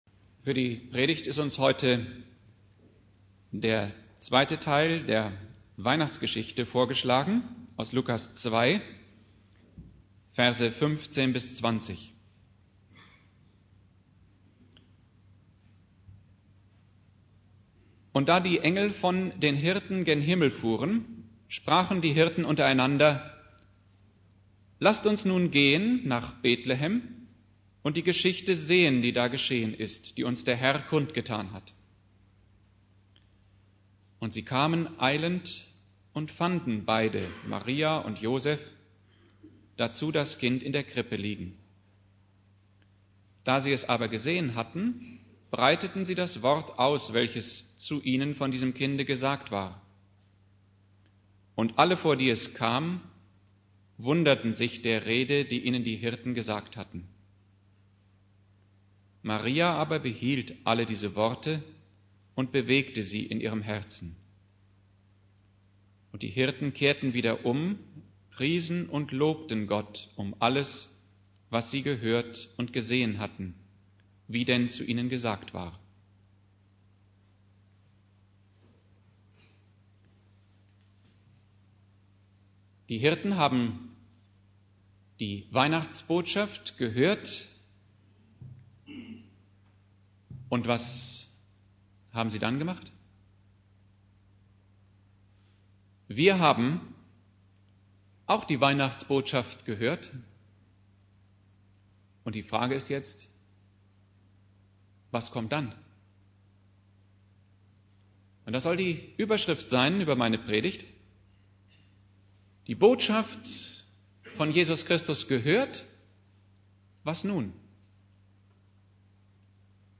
Predigt
1.Weihnachtstag Prediger